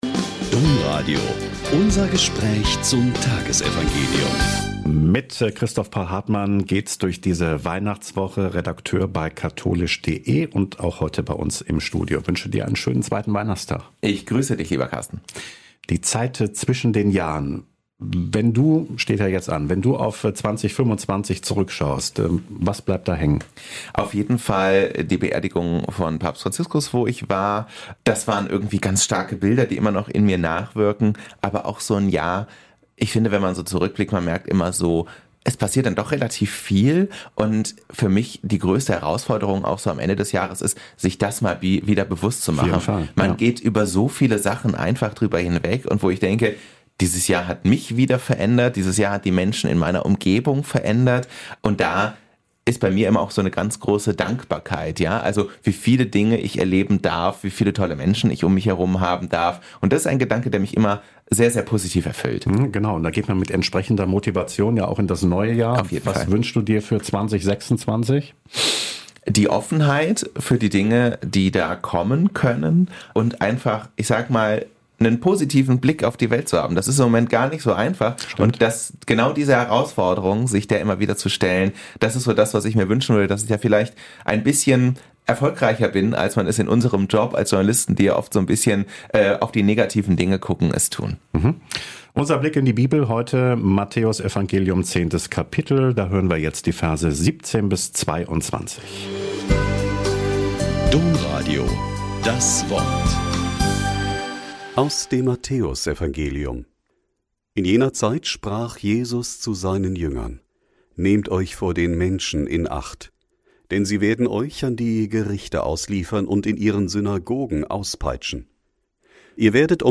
Mt 10,17-22 - Gespräch